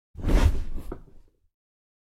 На этой странице собраны разнообразные звуки дивана: от скрипов старых пружин до мягкого шуршания обивки.
Звук падения человека на диван после тяжелого рабочего дня